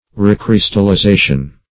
Search Result for " recrystallization" : The Collaborative International Dictionary of English v.0.48: Recrystallization \Re*crys`tal*li*za"tion\ (r[=e]*kr[i^]s`tal*l[i^]*z[=a]"sh[u^]n), n. (Chem.